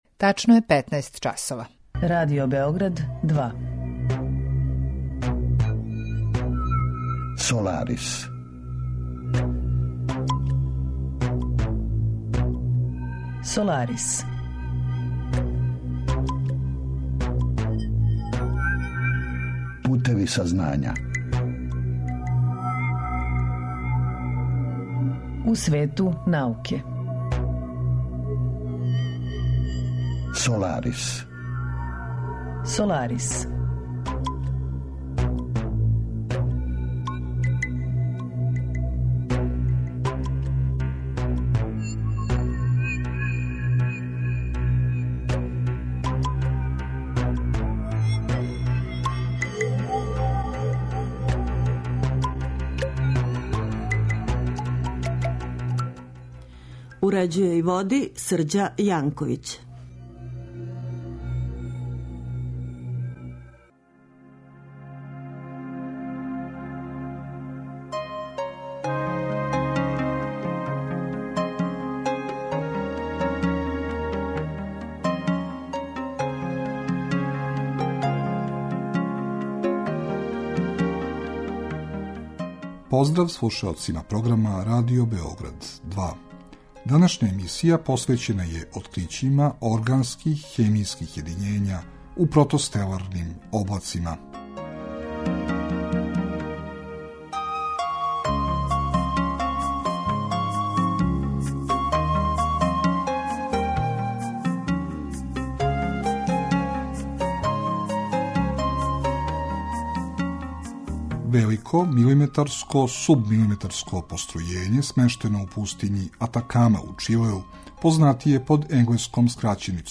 Саговорници у емисији обухватају научнике који се баве истраживањима из различитих области, од носилаца врхунских резултата и признања до оних који се налазе на почетку свог научног трагања, али и припаднике разноврсних професија који су у прилици да понуде релевантна мишљења о одговарајућим аспектима научних подухвата и науке у целини.